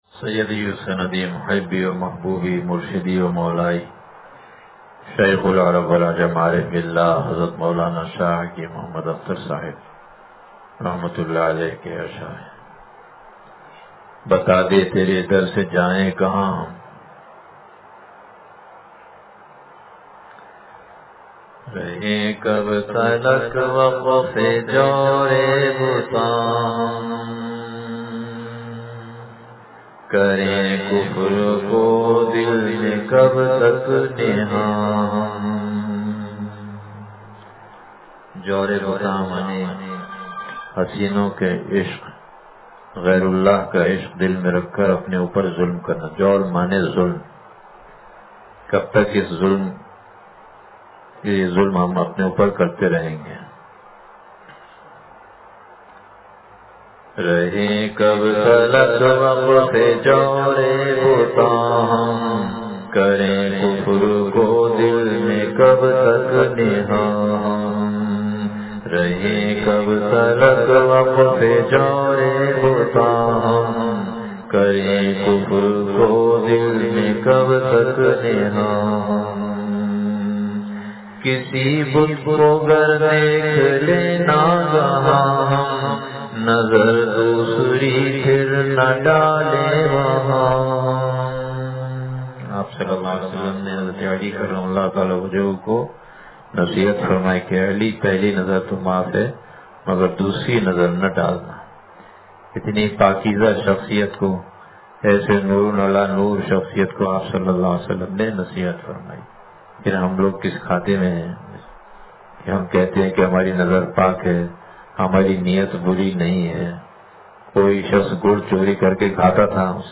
بتا دے ترے در سے جائیں کہاں ہم – مجلس بروز جمعرات – دنیا کی حقیقت – نشر الطیب فی ذکر النبی الحبیب صلی اللہ علیہ وسلم